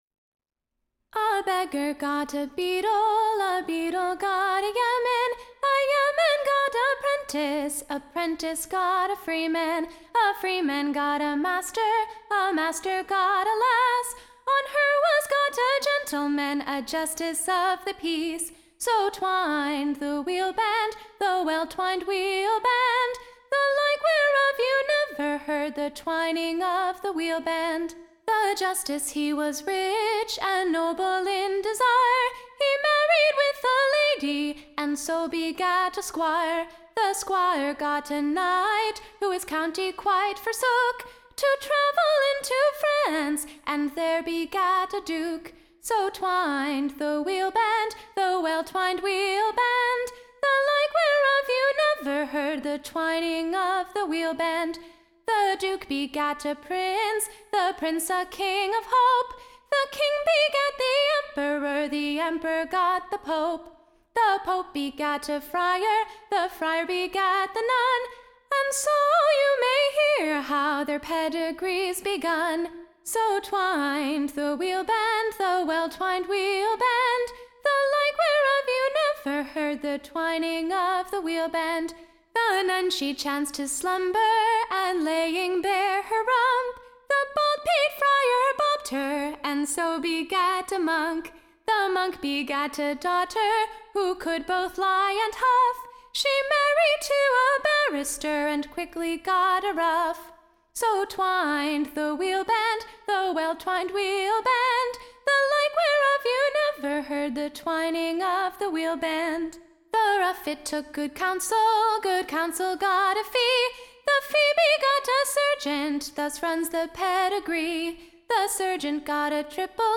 Recording Information Ballad Title The POPES Pedigree: / Or, the twineing of a Wheelband, shewing the rise and first Pedigrees of Mortals inhabit- / ing beneath the Moon.